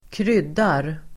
Uttal: [²kr'yd:ar]